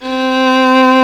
Index of /90_sSampleCDs/Roland - String Master Series/STR_Violin 1-3vb/STR_Vln3 % marc
STR VLN3 C 3.wav